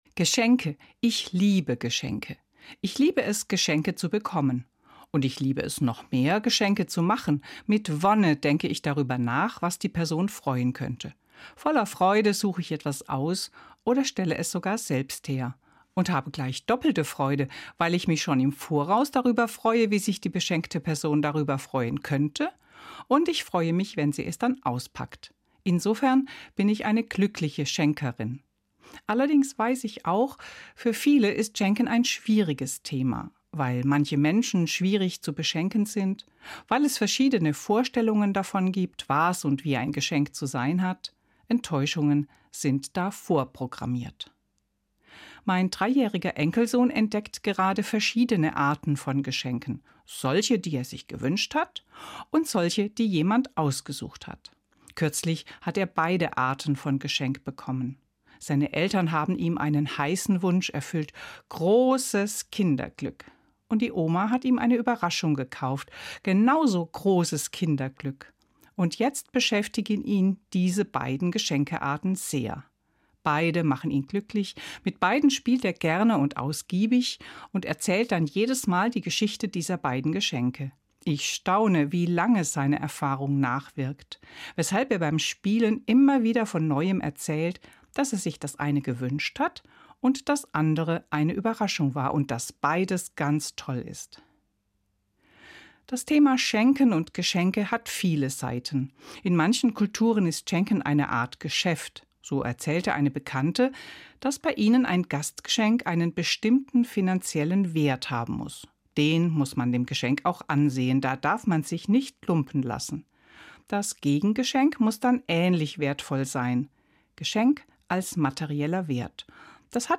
hr2 MORGENFEIER